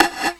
prcTTE44035tom.wav